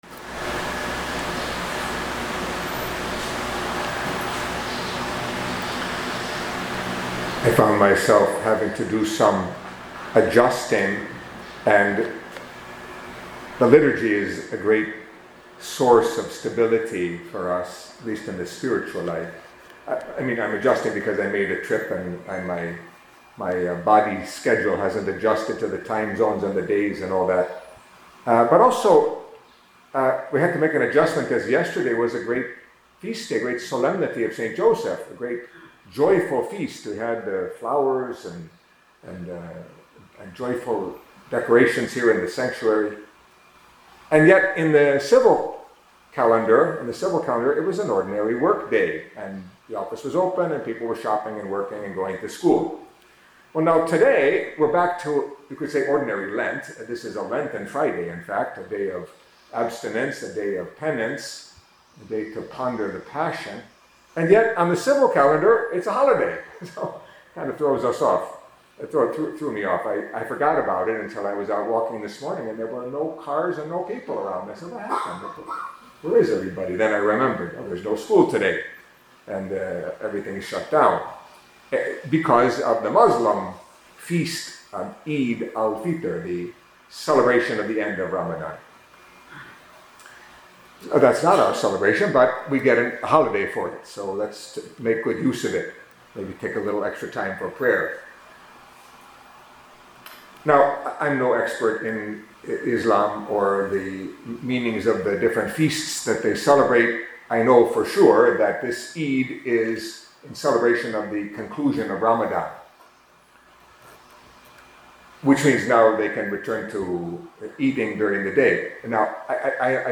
Catholic Mass homily for Friday of the Fourth Week of Lent